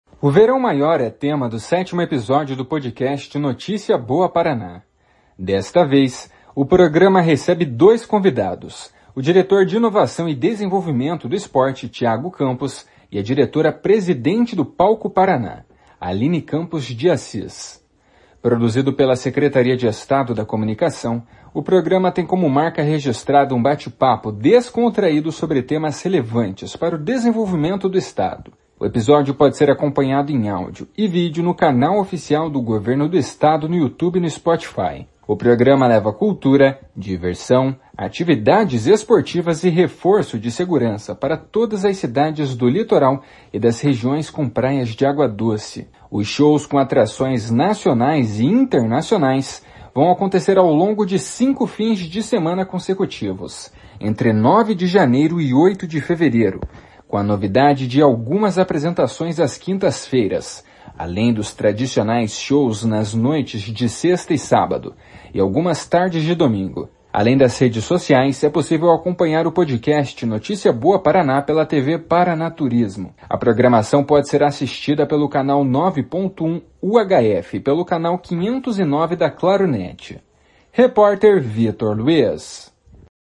O Verão Maior é tema do sétimo episódio do podcast Notícia Boa Paraná.
Produzido pela Secretaria de Estado da Comunicação, o programa tem como marca registrada um bate-papo descontraído sobre temas relevantes para o desenvolvimento do Estado.